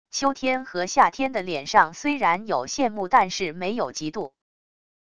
秋天和夏天的脸上虽然有羡慕但是没有嫉妒wav音频生成系统WAV Audio Player